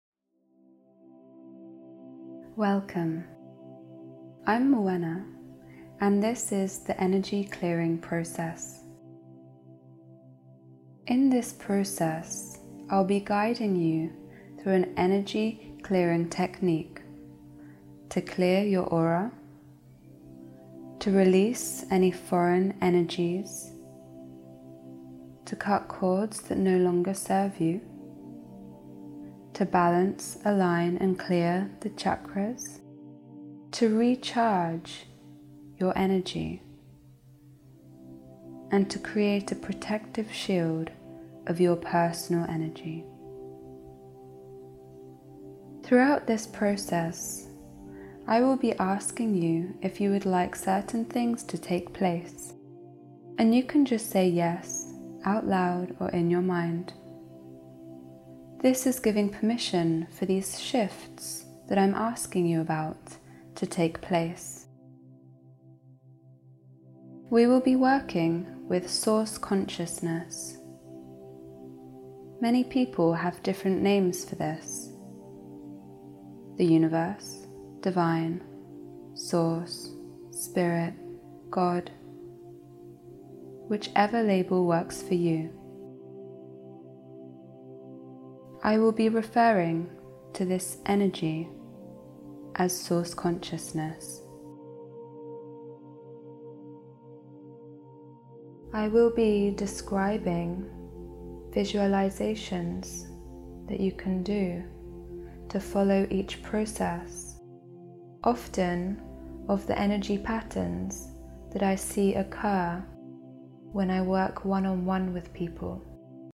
They use advanced Audio Entrainment techniques to allow Healing in the subconscious mind.